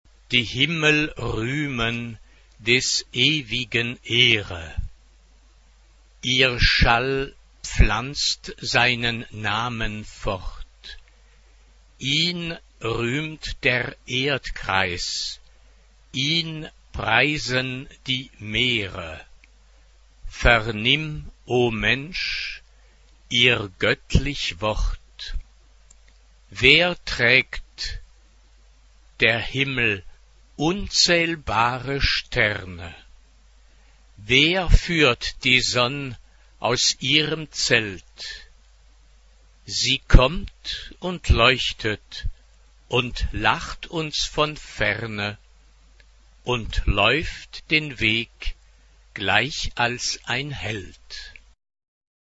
SATB (4 voix mixtes) ; Partition complète.
Genre-Style-Forme : Sacré ; Lied ; Harmonisation
Piano (1)
Tonalité : do majeur